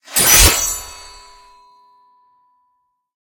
bsword3.ogg